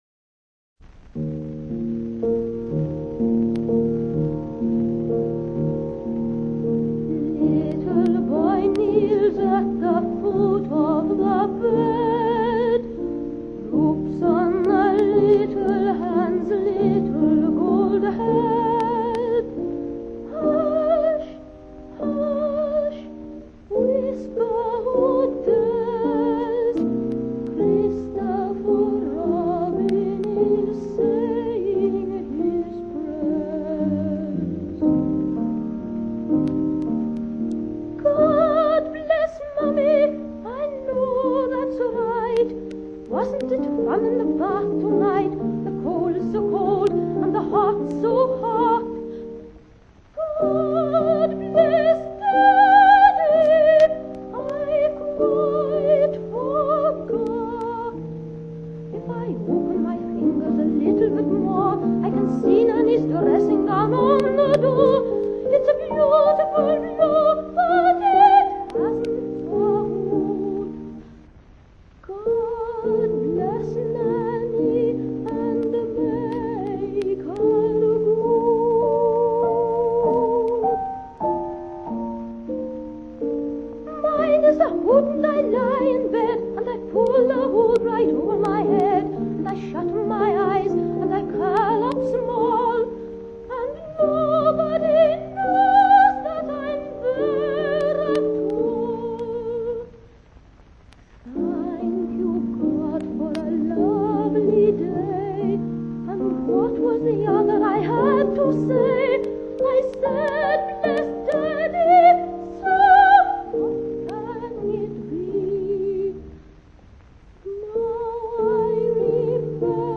Piano
Rec. Abbey Road Studio